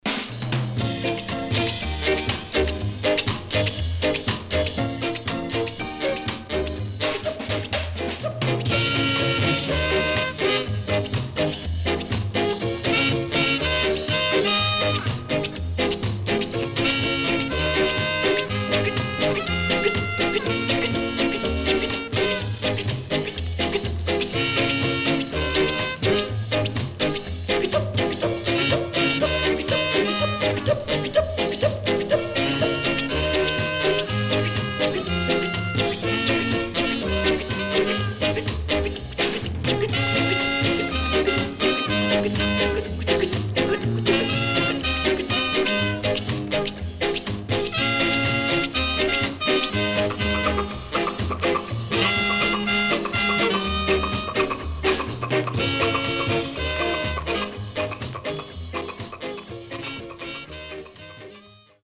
Rocksteady Foundation & Nice Ska Inst
SIDE A:少しチリノイズ入りますが良好です。